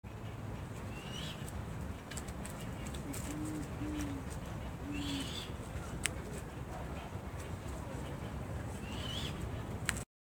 Great Horned Owl (Bubo virginianus)
Province / Department: Ciudad Autónoma de Buenos Aires
Location or protected area: Lago de Regatas
Condition: Wild
Certainty: Recorded vocal